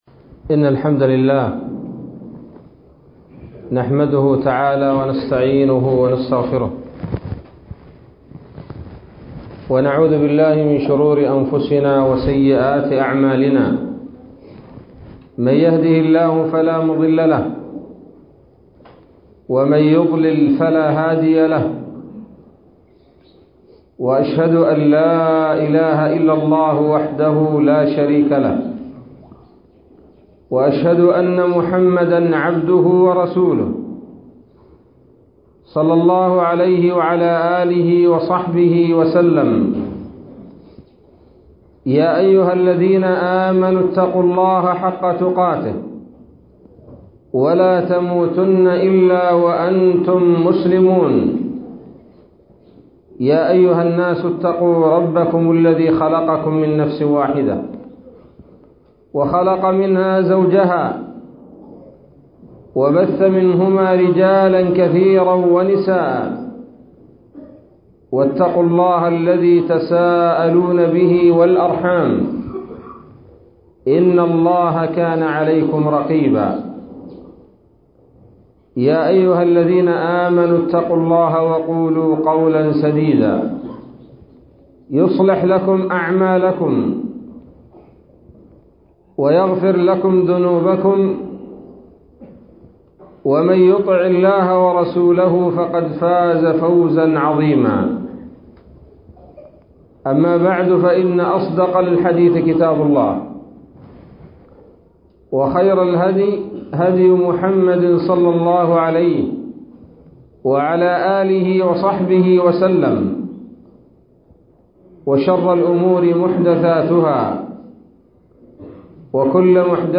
محاضرة بعنوان : ((الرفعة الحقيقية)) 21 ربيع أول 1437 هـ